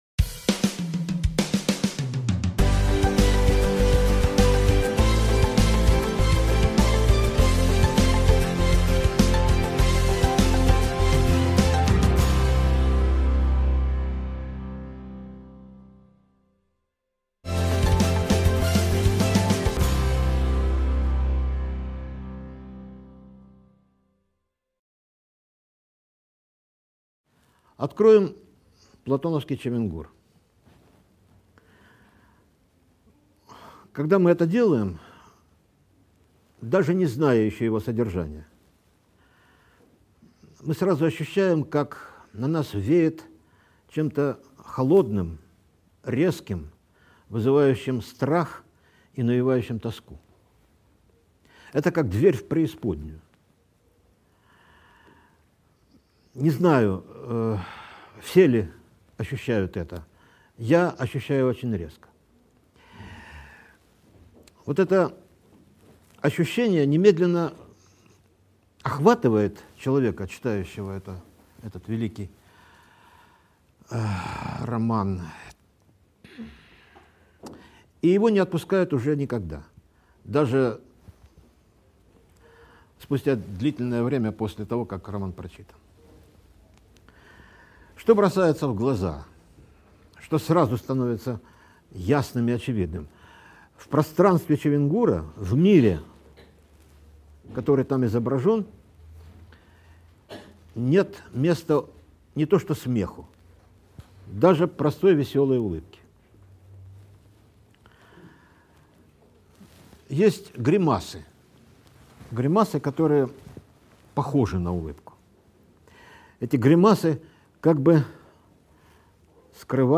Аудиокнига Тоска по бытию: Чехов и Платонов | Библиотека аудиокниг